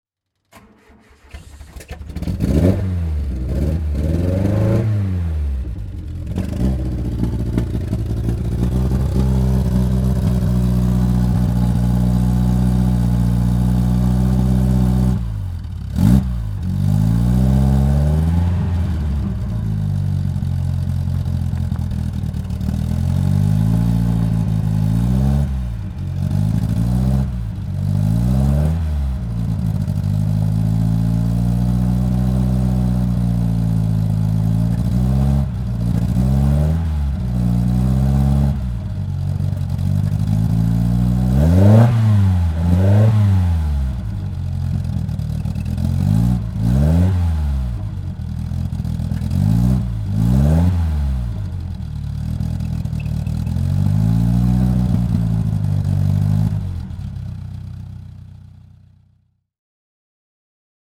Ginetta G4 - Starten und Leerlauf
Ginetta_G4_1967_QM.mp3